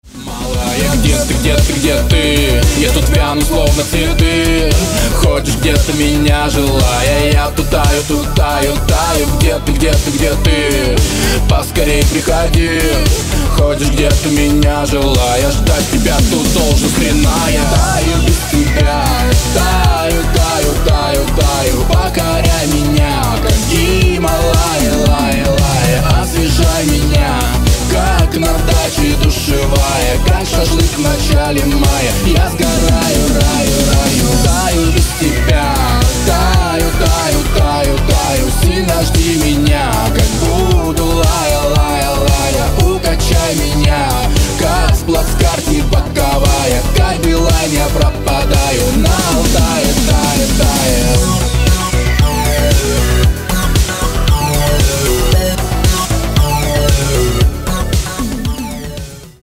• Качество: 256, Stereo
мужской вокал
громкие
веселые